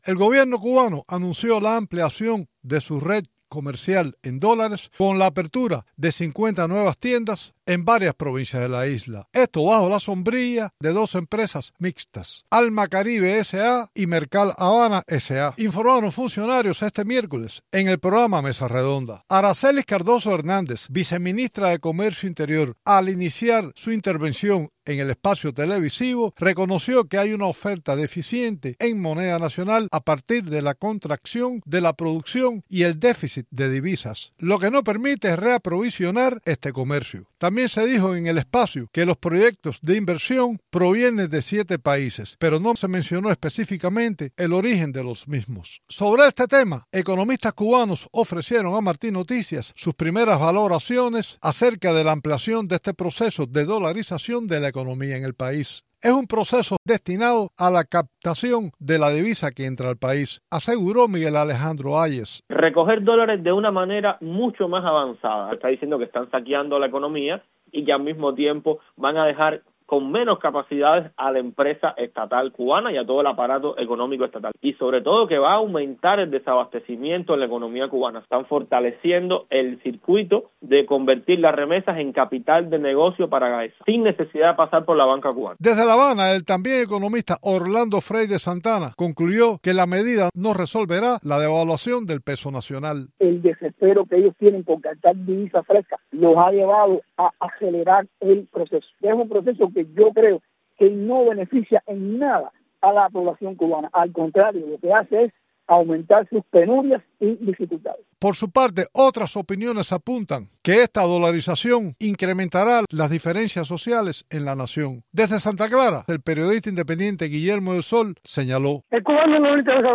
Las principales informaciones relacionadas con Cuba, América Latina, Estados Unidos y el resto del mundo de los noticieros de Radio Martí en la voz de nuestros reporteros y corresponsales